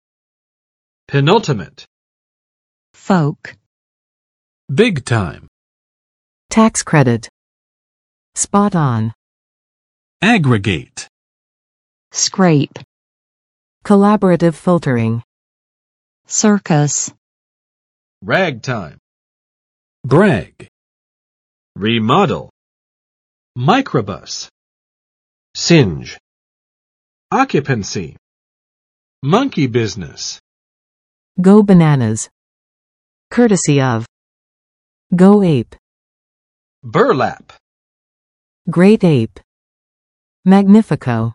[pɪˋnʌltəmɪt] adj.